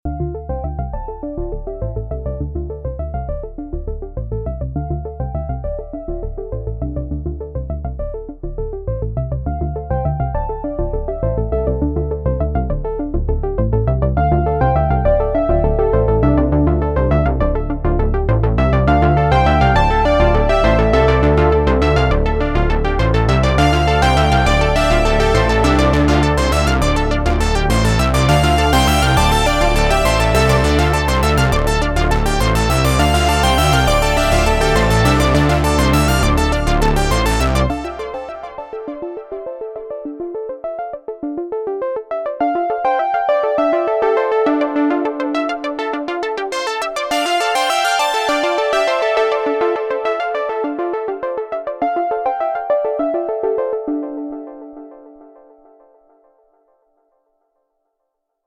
A4 and slowly turning the quick perf macro throughout